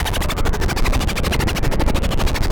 RI_ArpegiFex_95-04.wav